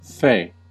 Ääntäminen
Vaihtoehtoiset kirjoitusmuodot fay fae Synonyymit fairy fairylike Ääntäminen US Tuntematon aksentti: IPA : /feɪ/ Haettu sana löytyi näillä lähdekielillä: englanti Käännöksiä ei löytynyt valitulle kohdekielelle.